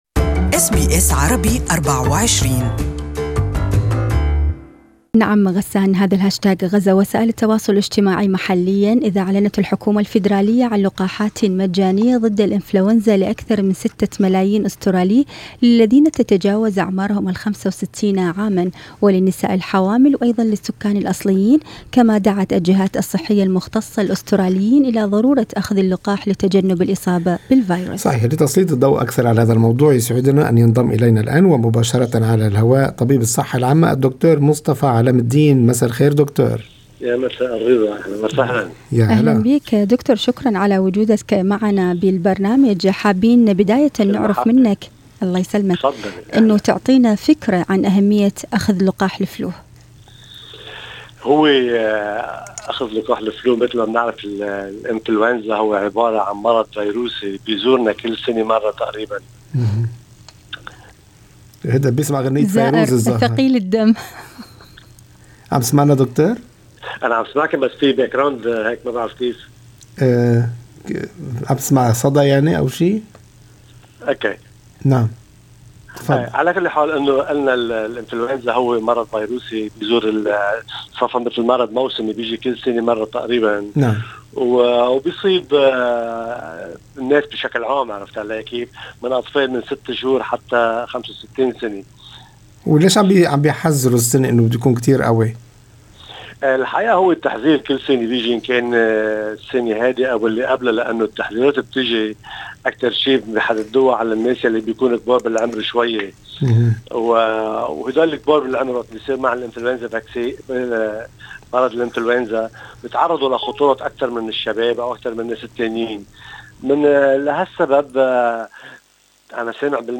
استمعوا إلى اللقاء المزيد في التدوين الصوتي اعلاه شارك